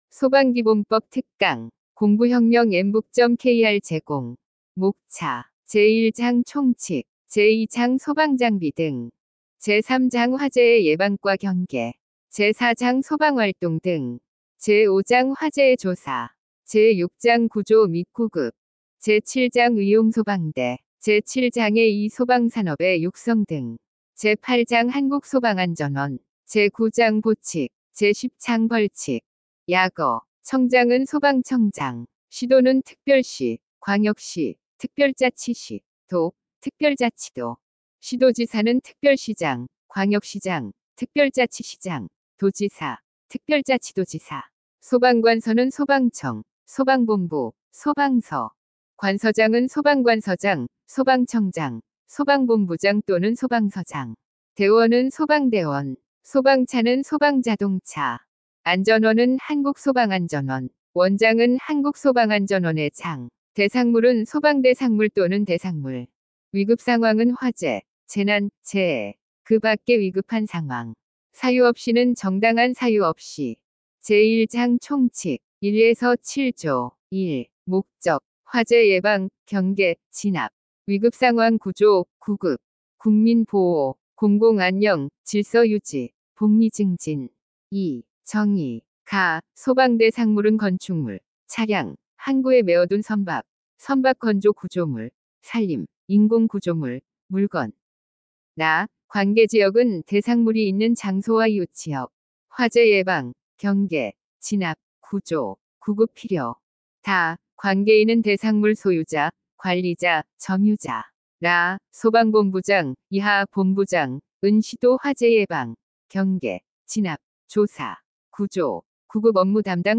강사 : 인공지능(AI)
– 인공지능 성우 이용 오디오 강의
▶ 강의 샘플(4분)
소방기본법-특강-샘플.mp3